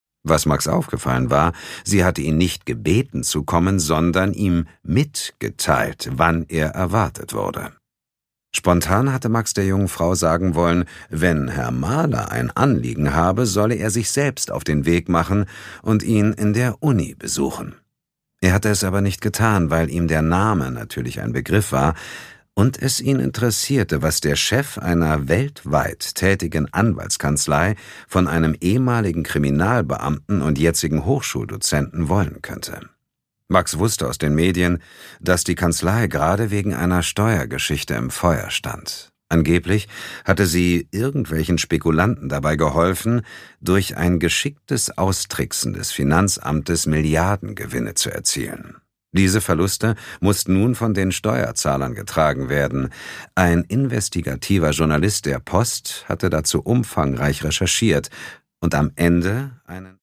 Produkttyp: Hörbuch-Download
Gelesen von: Sascha Rotermund, Dietmar Wunder